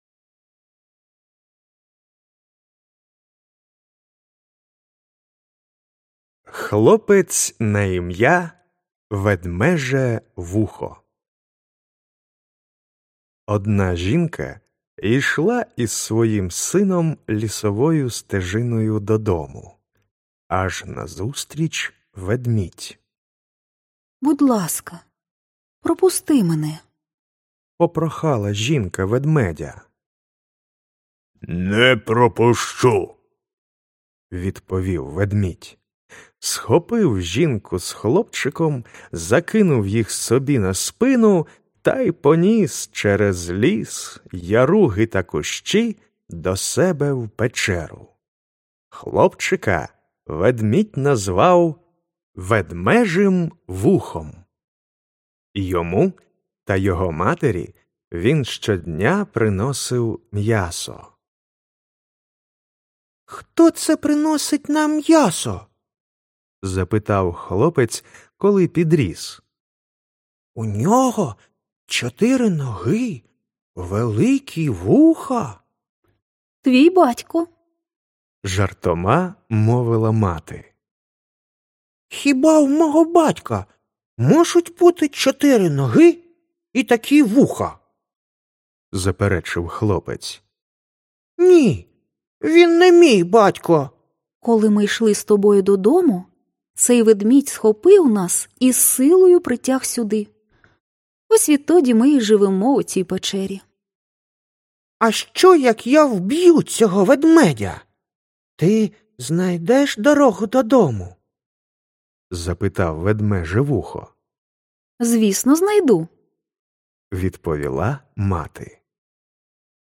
Аудіоказка Хлопець на ім’я Ведмеже Вухо